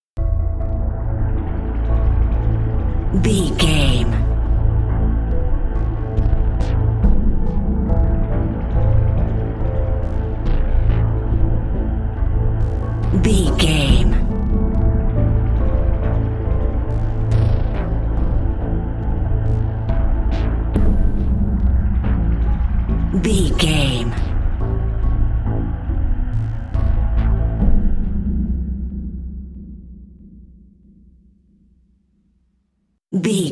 In-crescendo
Thriller
Aeolian/Minor
Slow
piano
synthesiser